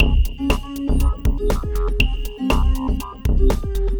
Lab Work (Full) 120BPM.wav